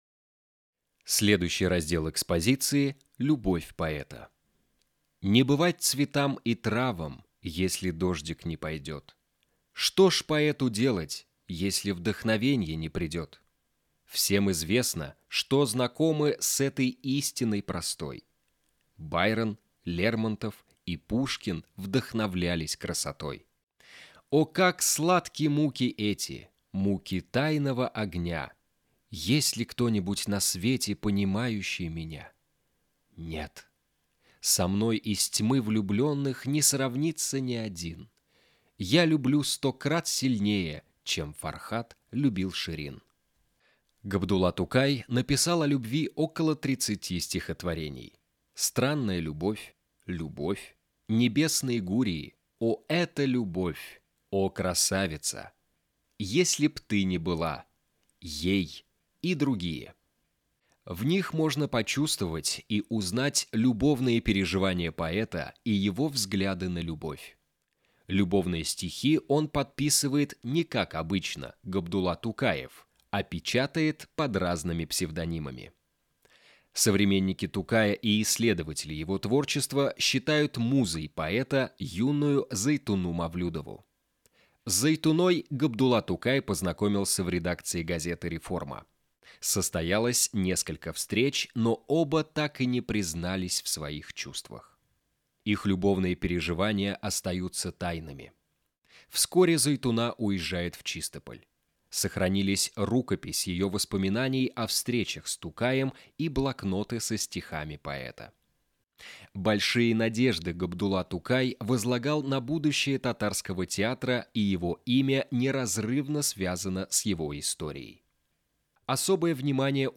1. «Литературный музей Г. Тукай – Аудиоэкскурсия. Зал “Любовь поэта”. На русском языке» /
muzey-G.-Tukay-Audioekskursiya.-Zal-Lyubov-poeta.-Na-russkom-yazyke-stih-club-ru.mp3